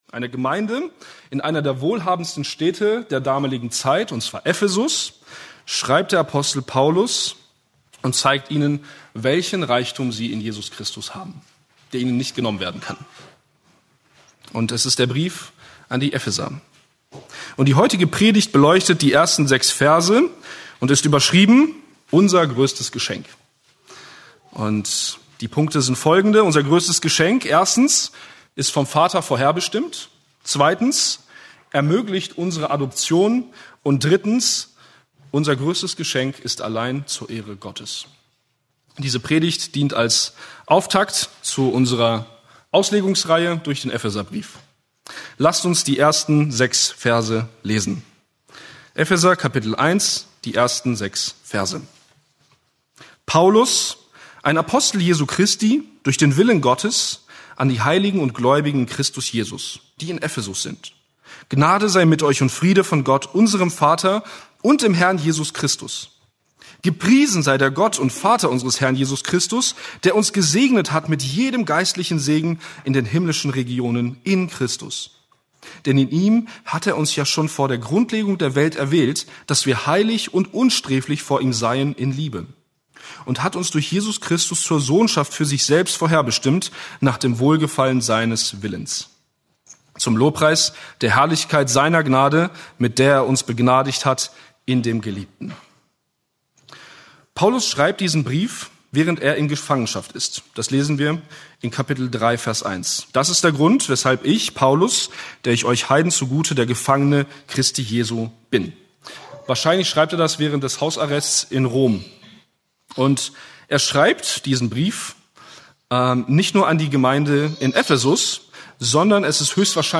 Eine predigt aus der serie "Epheser."